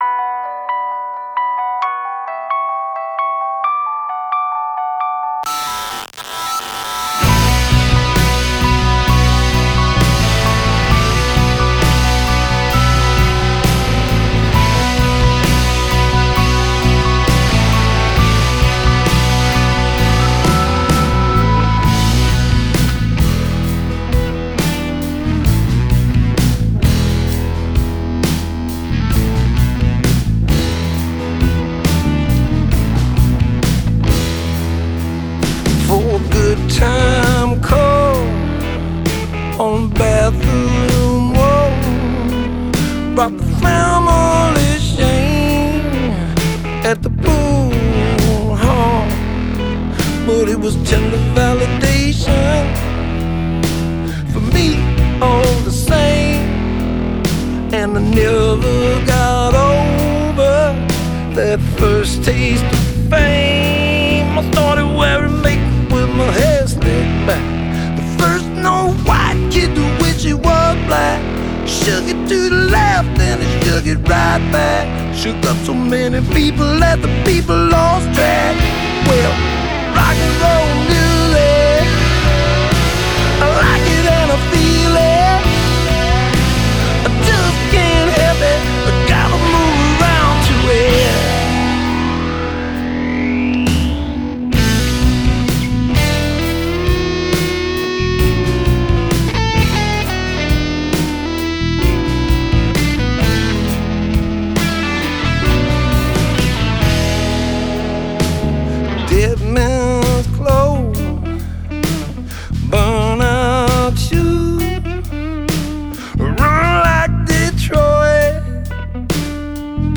Genre: Folk Rock, Blues, Americana